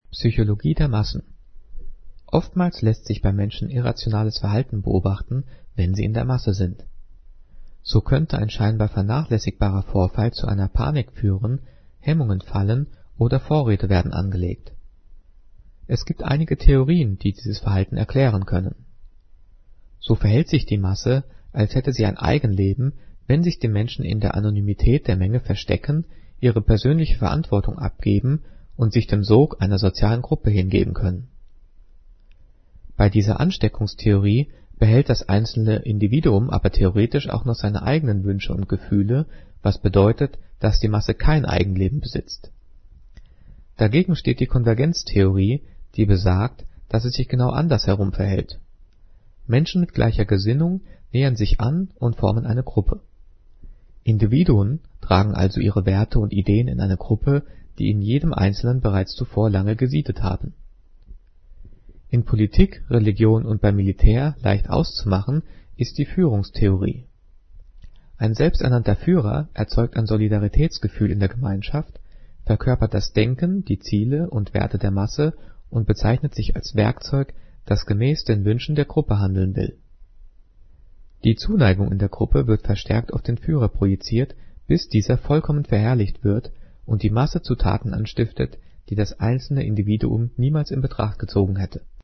Diktat: "Psychologie der Massen" - 9./10. Klasse - Umlaute
Gelesen: